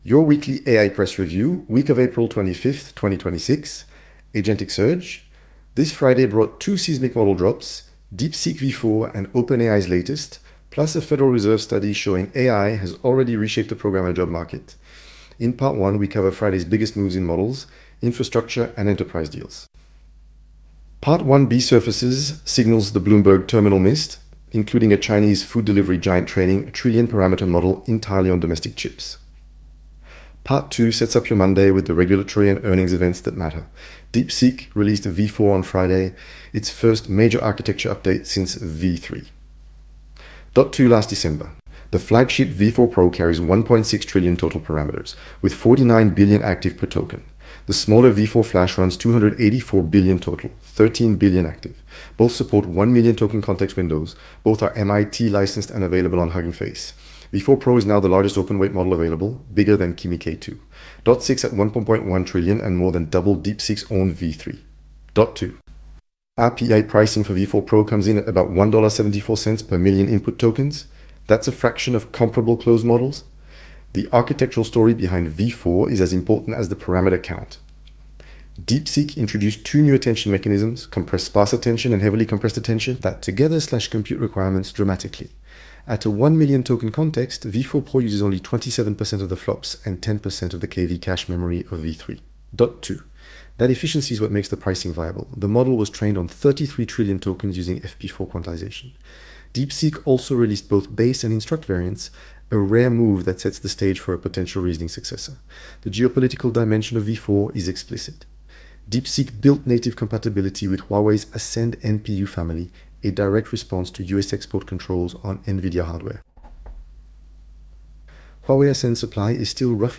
Synthetic in voice, genuine in substance. Now presented by an AI clone of your devoted host. And yes, the accent is... how you say... more French than the man himself.